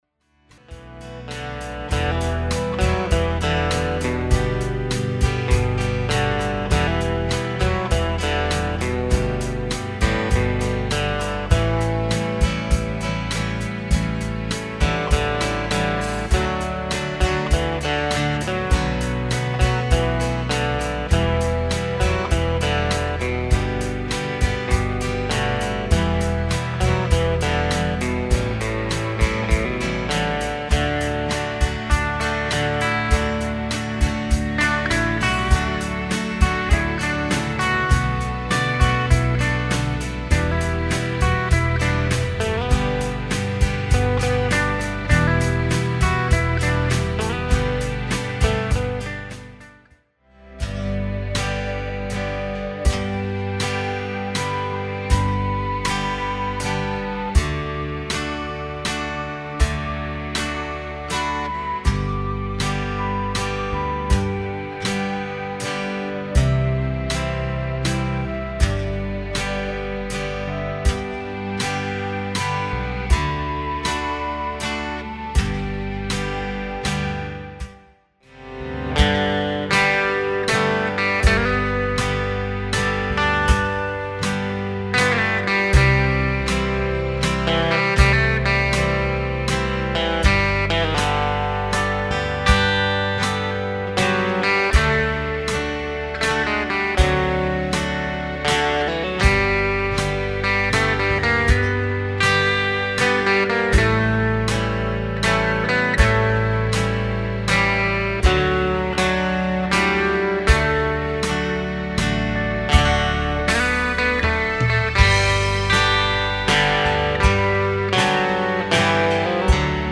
• The Rock Strum, Bluegrass and Waltz rhythm patterns
Song Samples